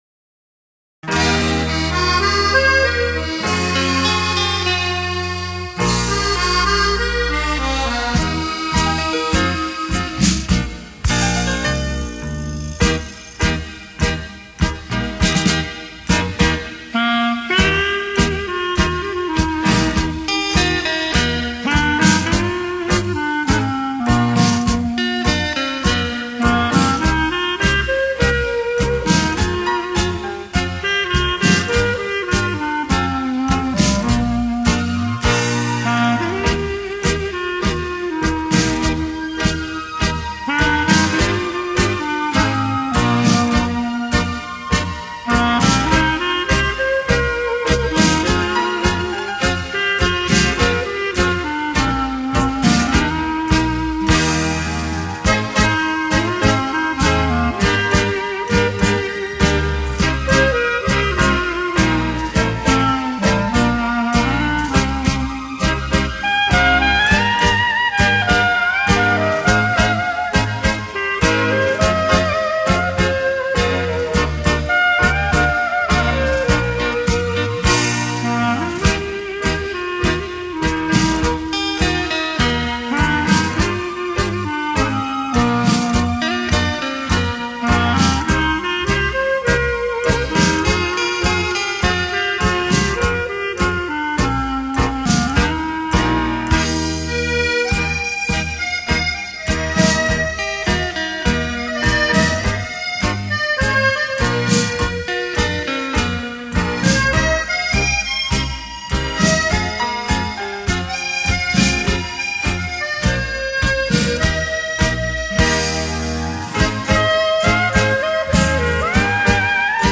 探戈舞曲《朦胧的灯光